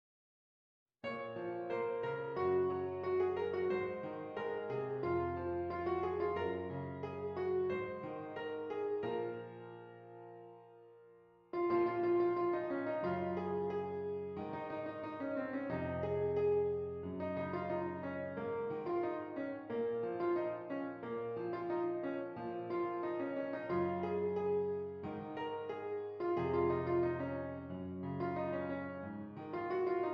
B Flat Minor
Moderate